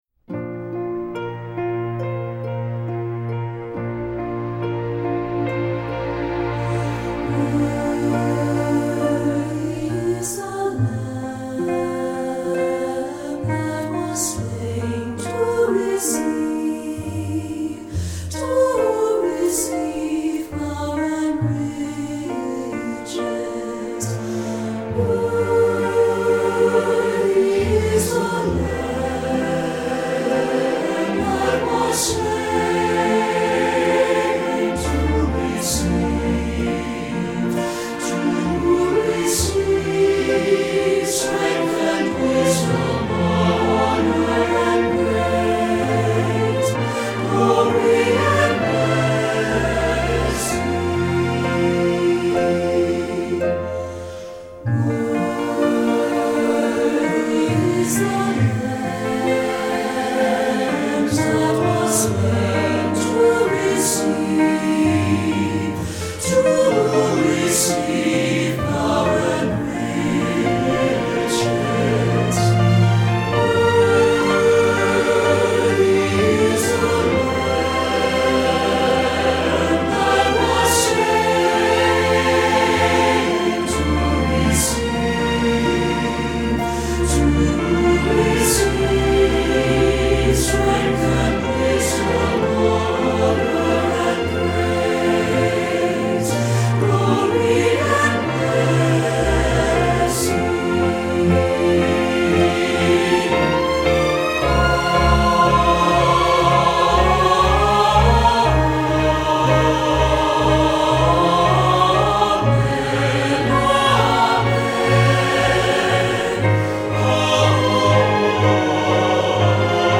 Guitar Sacred|Gospel Mixed
Voicing: SATB